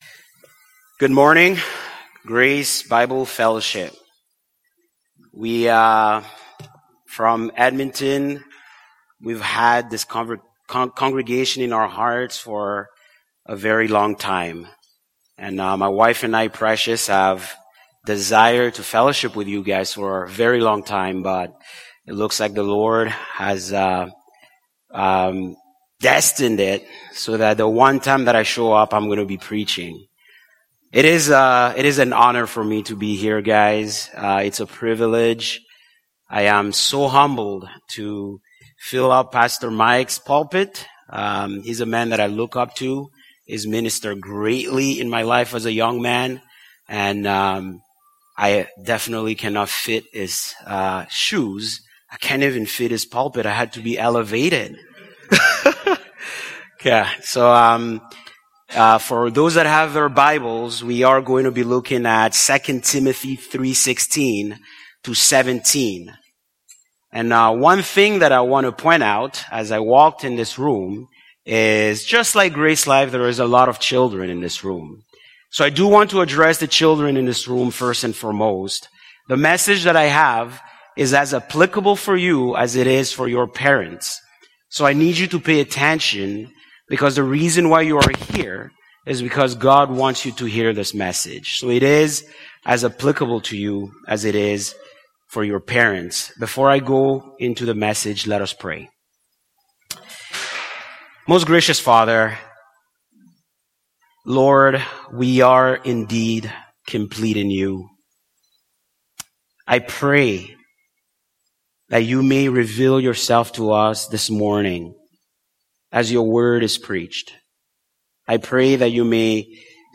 Category: Sermon